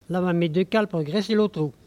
Saint-Urbain
Catégorie Locution